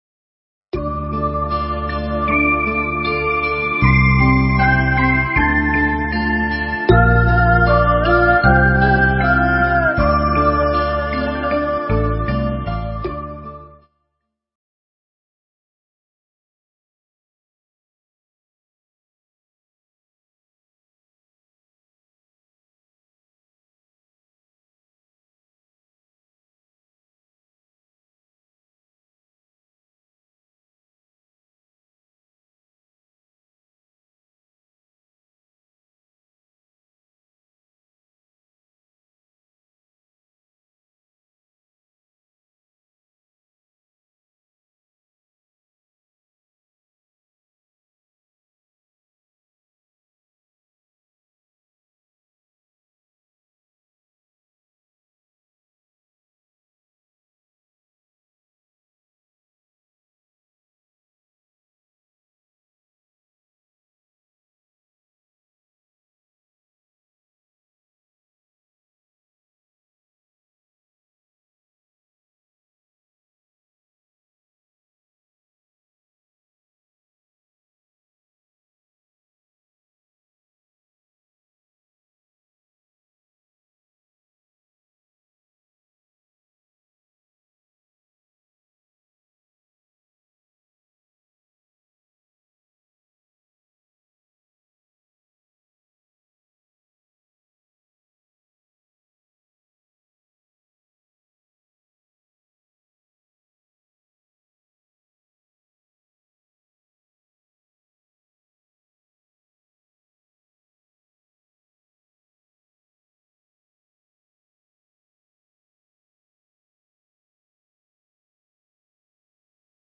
Tải mp3 Pháp Thoại Phật Giáo Đời Trần – Hòa Thượng Thích Thanh Từ ngày 16 tháng 11 năm 1996